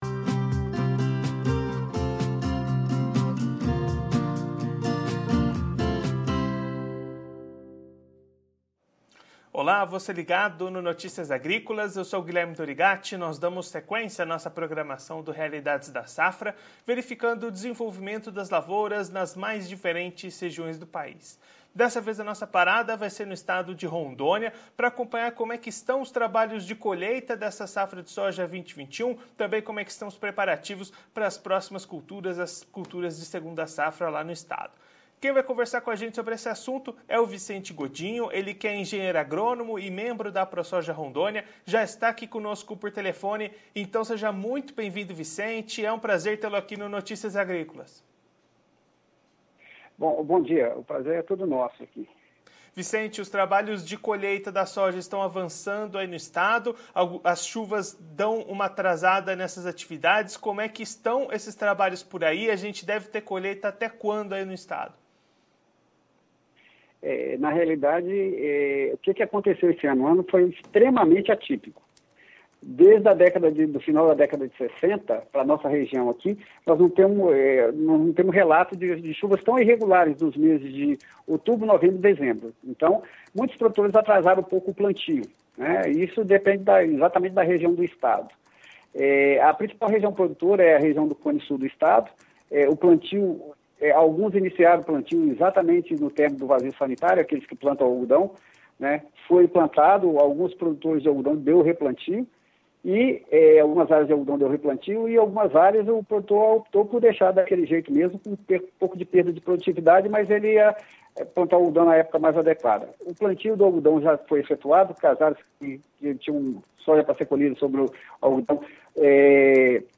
Confira a íntegra da entrevista com o engenheiro agrônomo e membro da Aprosoja Rondônia no vídeo.